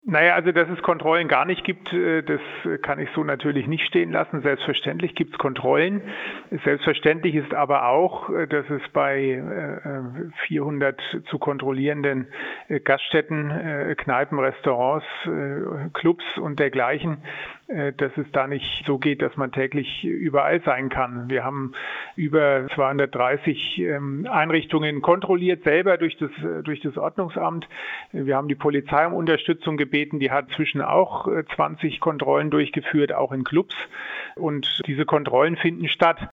Impfkapazität, Boostern und 3G-Kontrollen: Schweinfurts Ordnungsreferent Jan von Lackum im Interview - PRIMATON